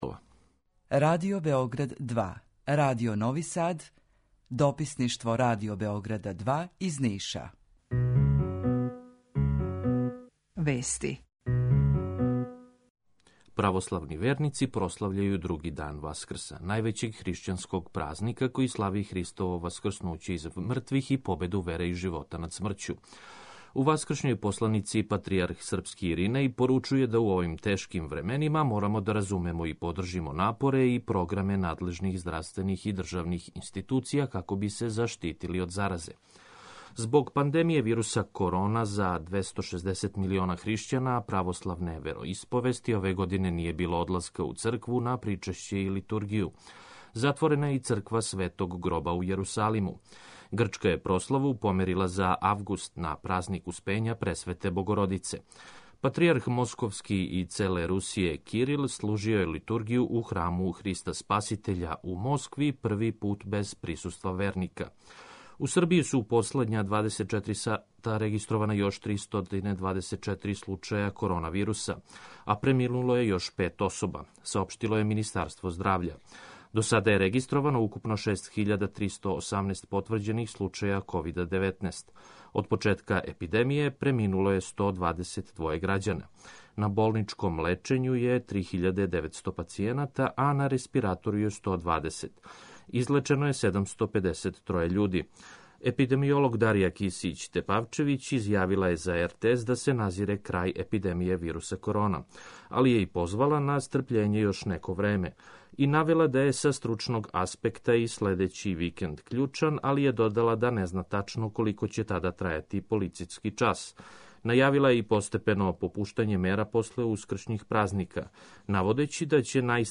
Јутарњи програм из три студија
Jутарњи програм заједнички реализују Радио Београд 2, Радио Нови Сад и дописништво Радио Београда из Ниша.